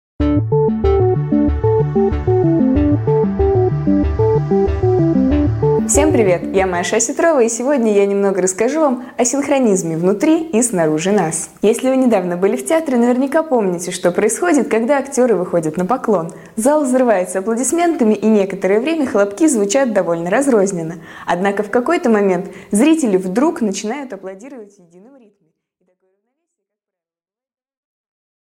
Аудиокнига 5 минут О ритме Вселенной | Библиотека аудиокниг